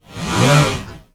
ROBOTIC_Movement_02_mono.wav